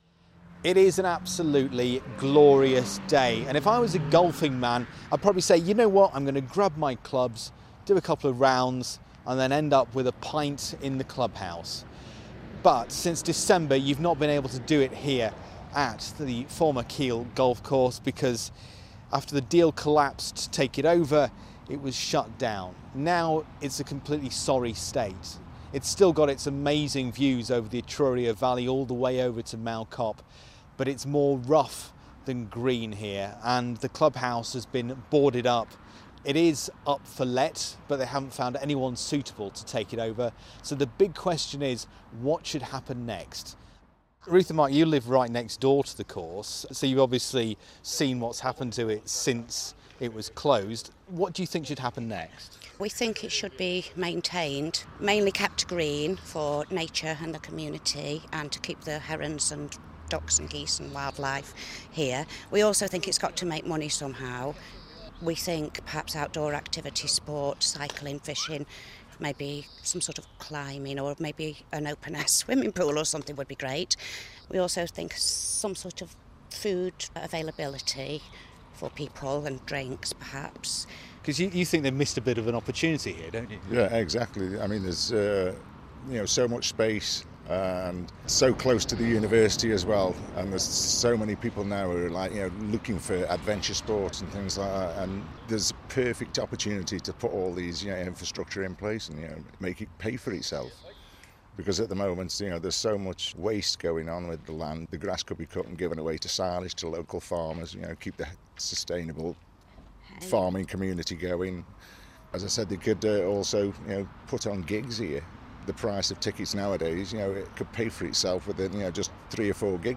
Newcastle Borough Council is asking what the former Keele Golf Course should be used for over the next three years. I've been asking what local people think....